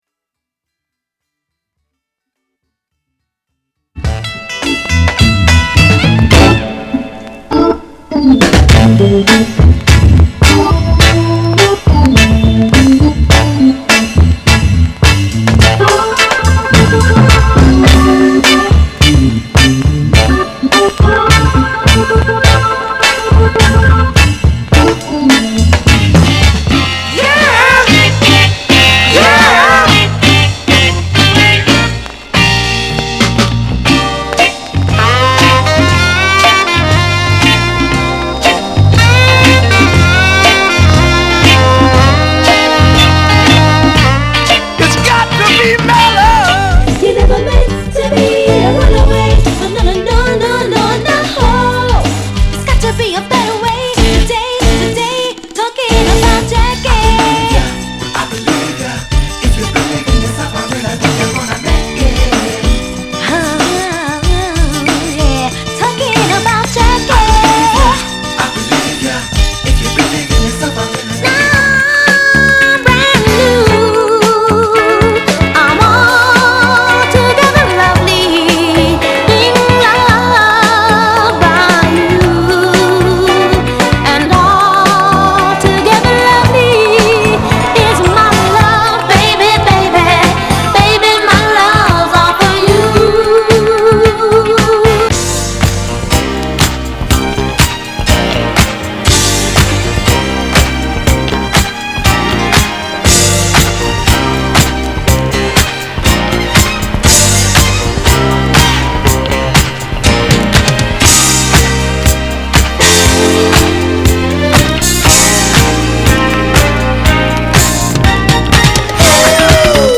/盤質/両面やや傷あり再生良好です/US PRESS
類別 R&B、靈魂樂